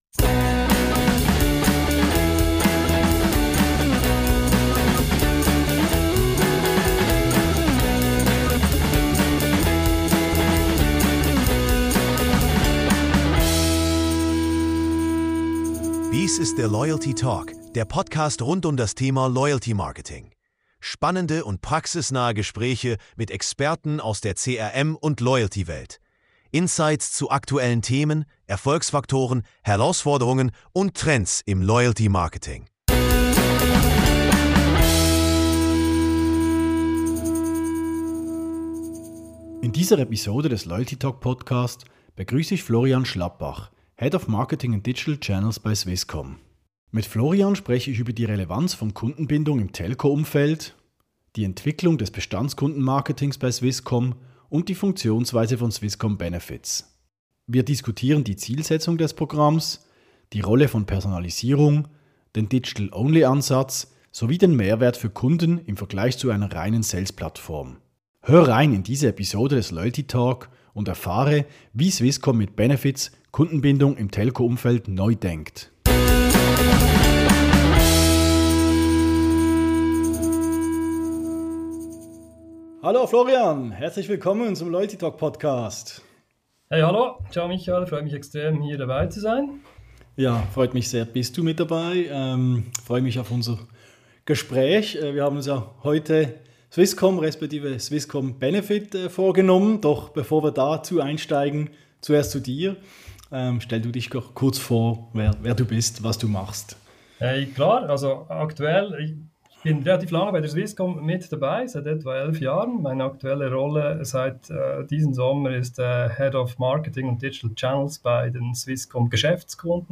Der Loyalty Talk ist ein Podcast rund um das Thema Loyalty-Marketing. Aufschlussreiche und praxisnahe Gespräche mit Experten aus der CRM- und Loyalty-Welt und Insights zu aktuellen Themen, Erfolgsfaktoren, Herausforderungen und Trends im Loyalty-Marketing.